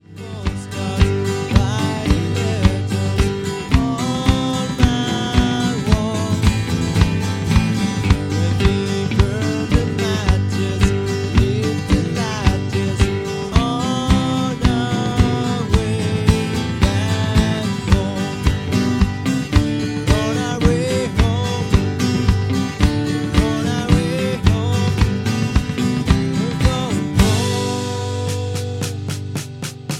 Backing track Karaoke
Rock, 1970s